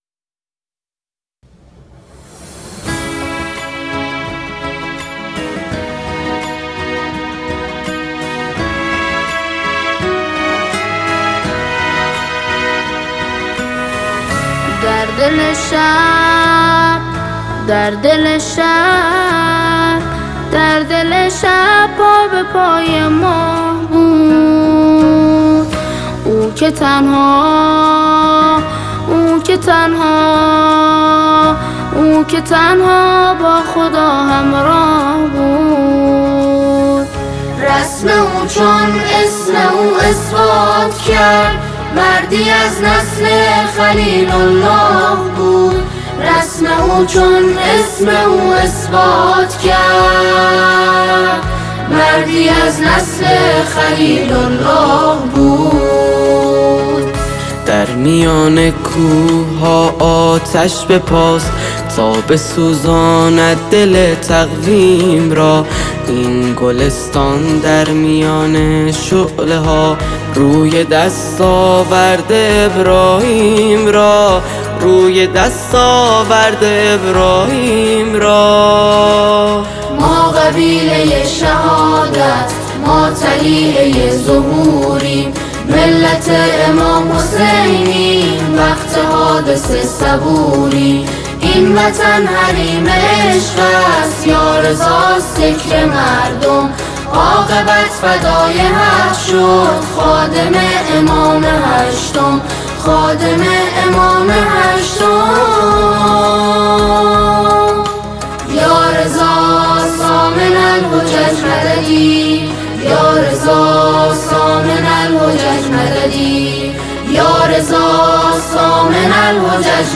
سرود